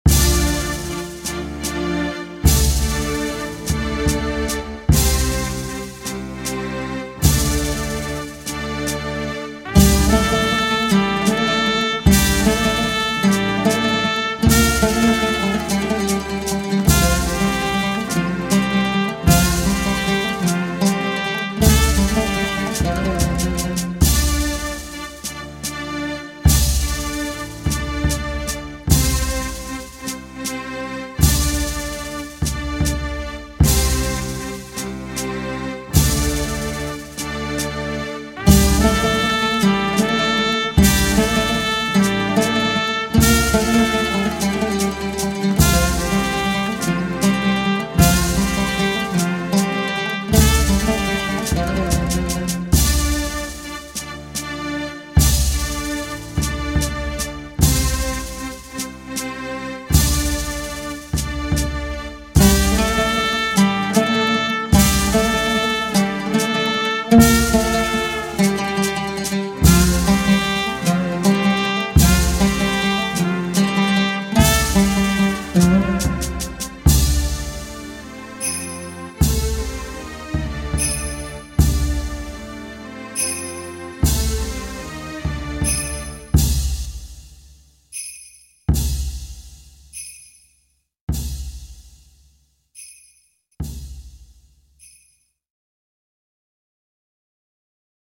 آهنگ بی کلام غمگین مذهبی با طبل و سنج و دمام